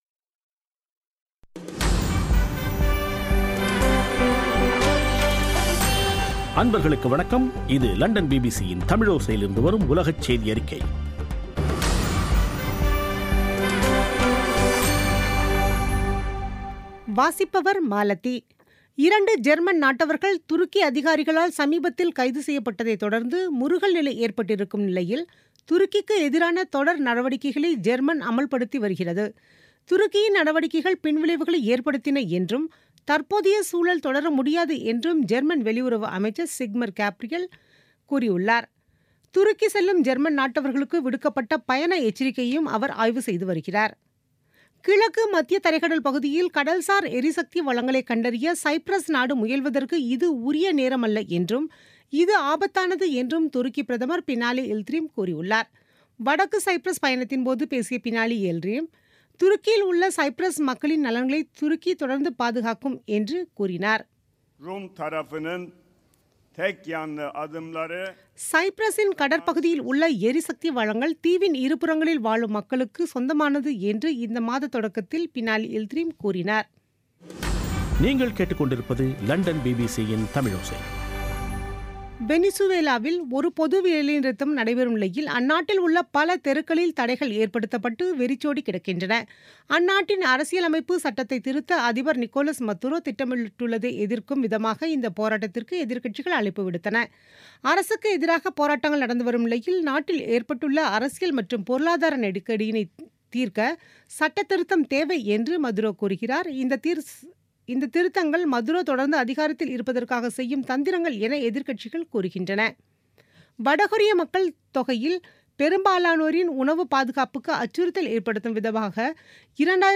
பிபிசி தமிழோசை செய்தியறிக்கை (20/07/2017)